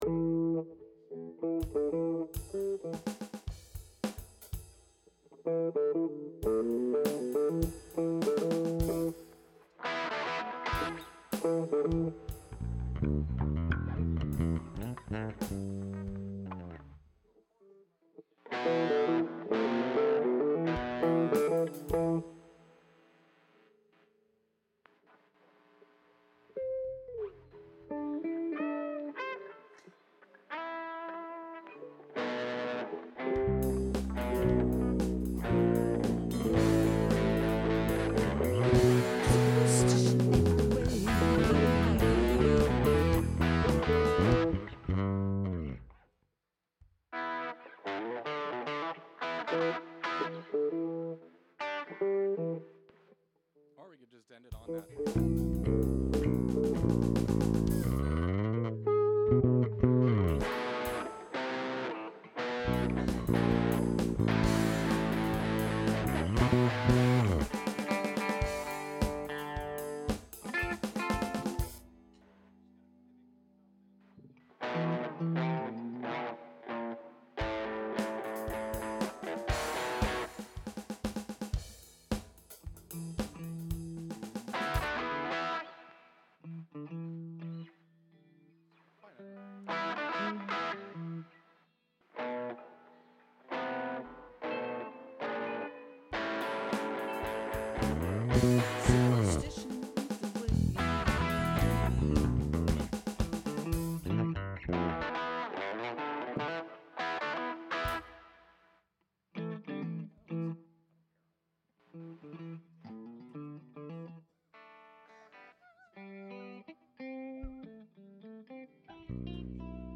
Index of /DDOS/2018-12-15 JASS Practice/Mixdown/22 endish - work on ending
Strings Mix.mp3